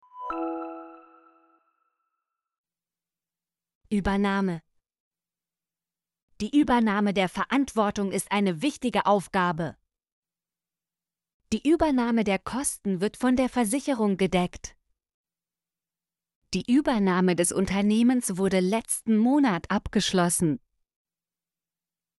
übernahme - Example Sentences & Pronunciation, German Frequency List